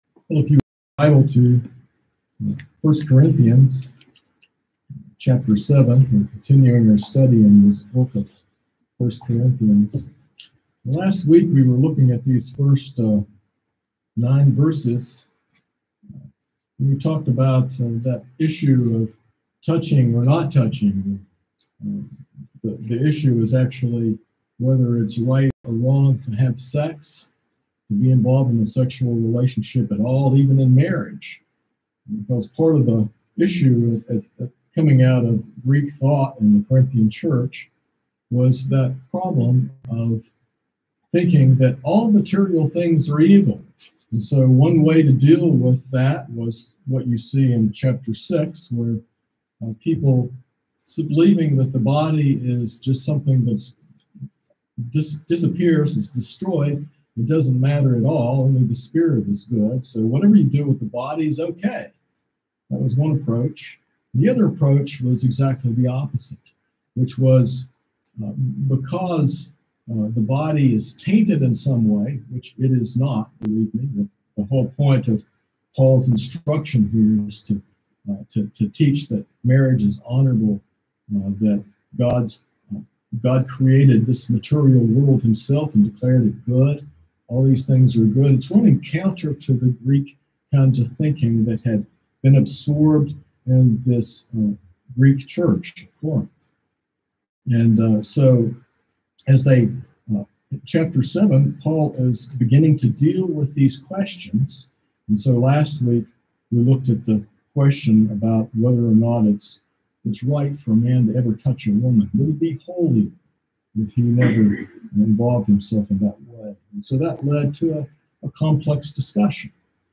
This sermon delves into 1 Corinthians 7, exploring biblical principles of marriage and divorce. It addresses the question of whether believers can divorce, especially in cases of mixed marriages, and examines the cultural and scriptural context of Paul’s instructions.
sermon-7-25-21.mp3